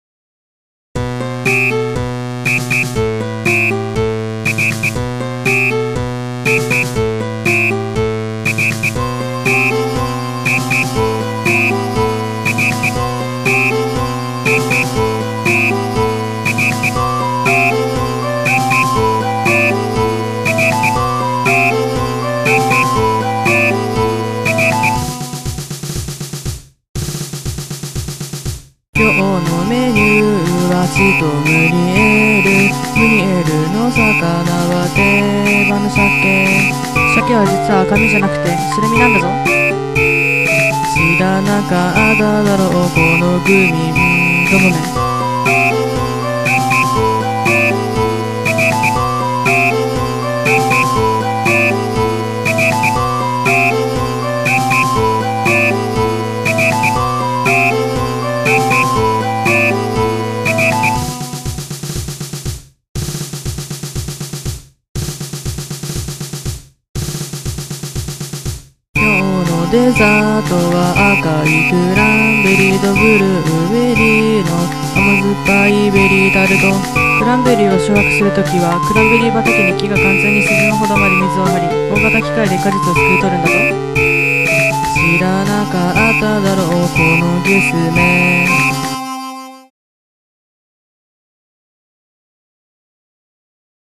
ジャンル：キャラソン
結構おとなしめな感じに仕上がりました∩(・ω・)∩所々の台詞以外はｗｗｗ
サイト初の声有り音楽ｗｗｗｗｗ